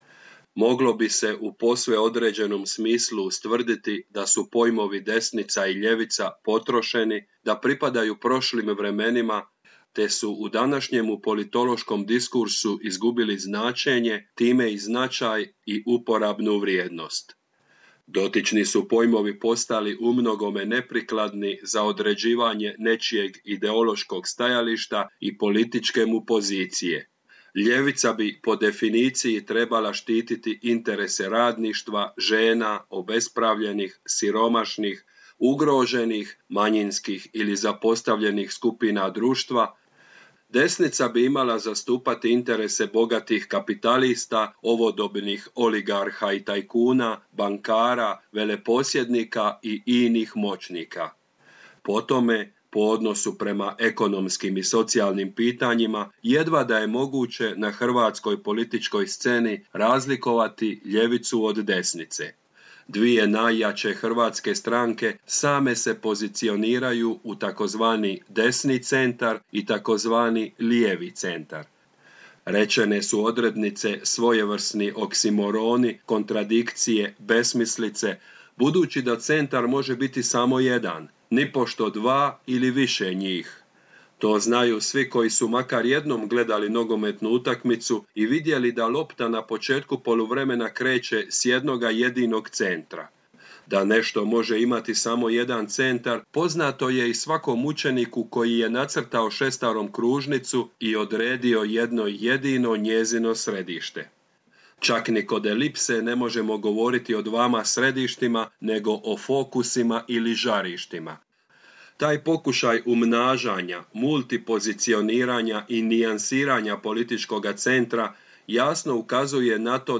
Osvrt na knjigu Yuvala Levina ″Ljevica i desnica